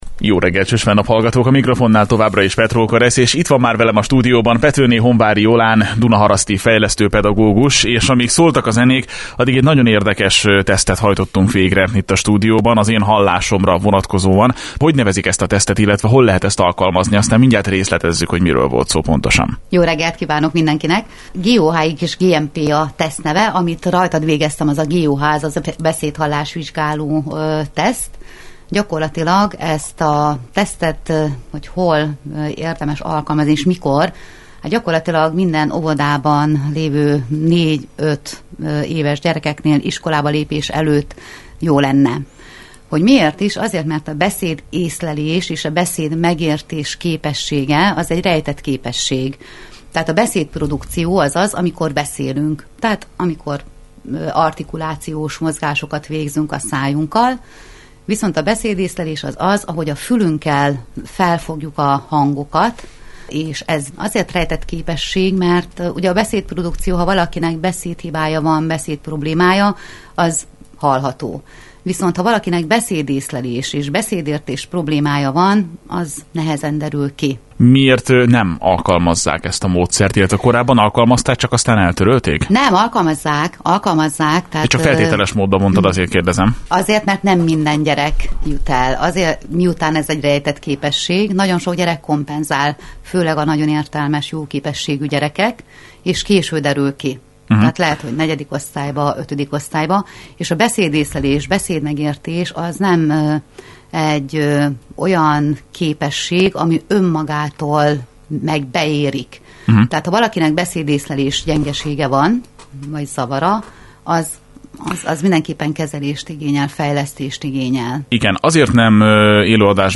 A Lakihegy Rádióban a beszédészlelésről és a beszédmegértésről beszélgettünk.